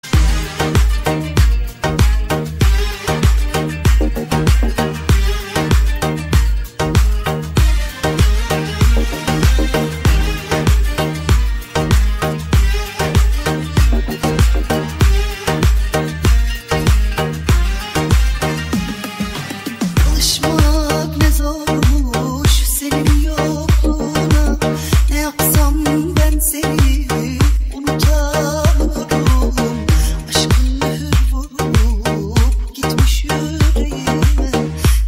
Kategori Elektronik